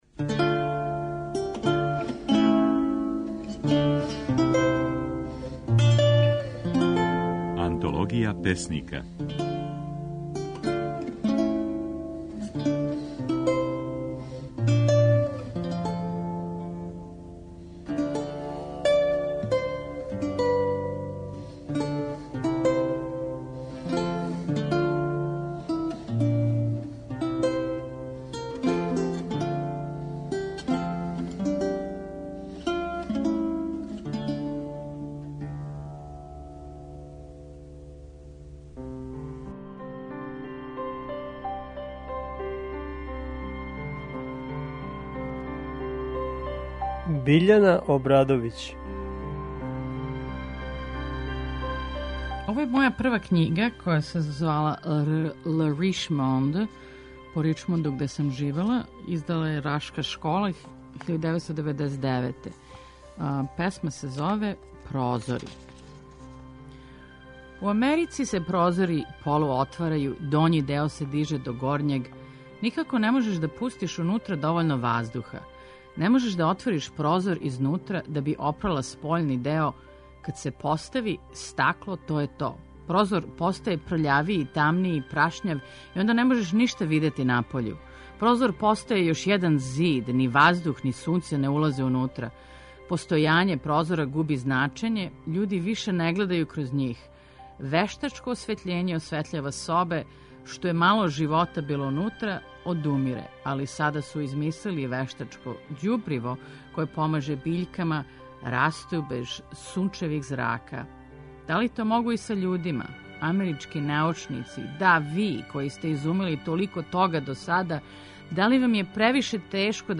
можете слушати како своје стихове говори песникиња